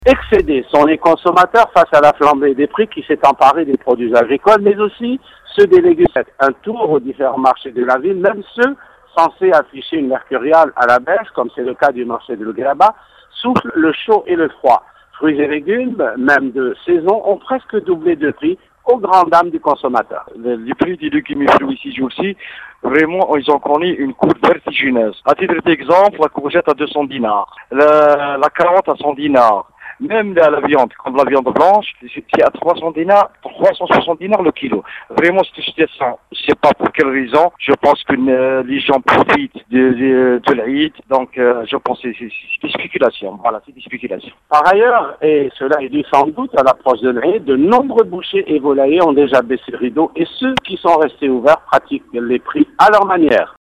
Les prix des fruits et légumes explosent - Reportage